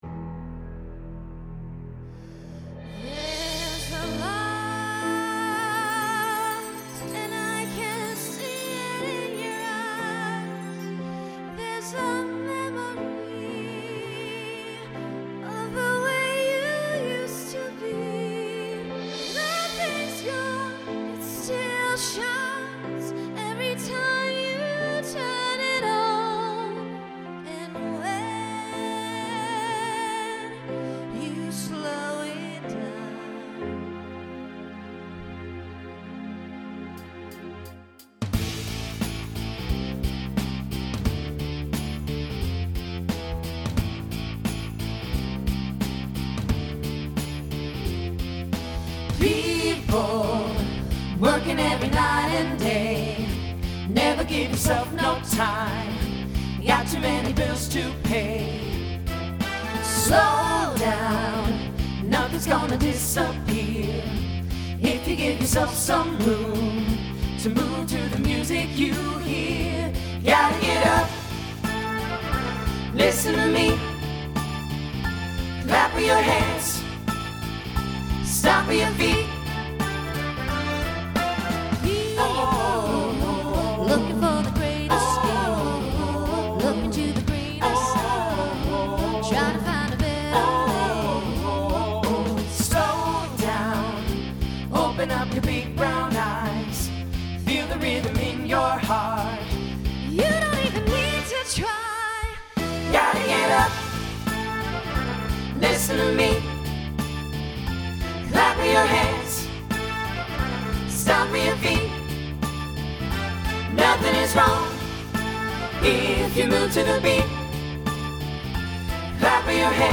Voicing SATB Instrumental combo Genre Pop/Dance , Rock
Mid-tempo